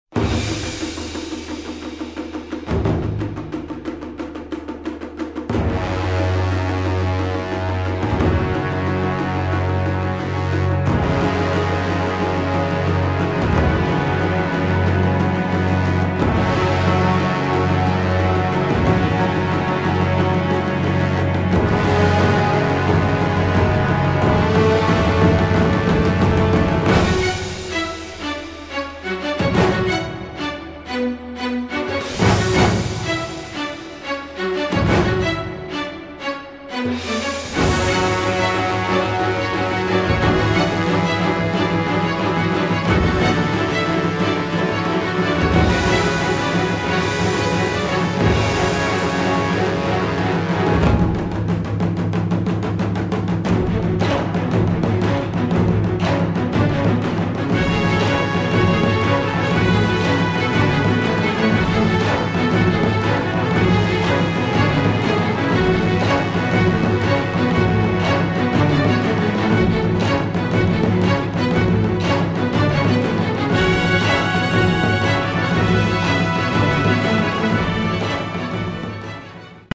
Wer's actionreich bzw. dramatisch mag...